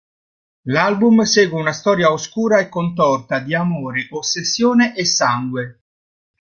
Pronúnciase como (IPA) /konˈtɔr.ta/